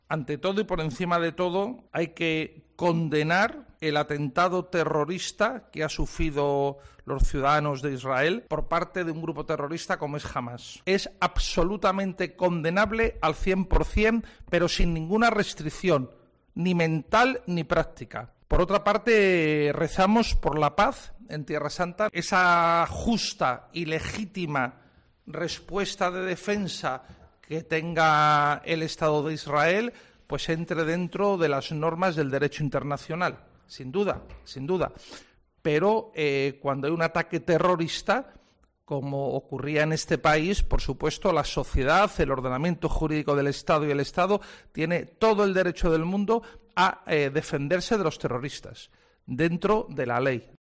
El secretario general de la CEE ha hablado de la triste situación en Israel durante la presentación de la campaña del Domund 2023 en Toledo